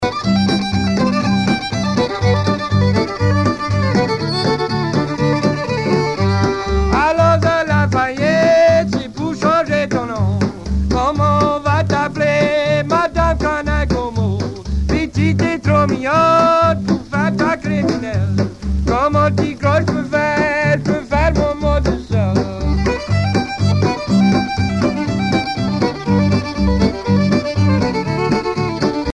Louisiane
Pièce musicale éditée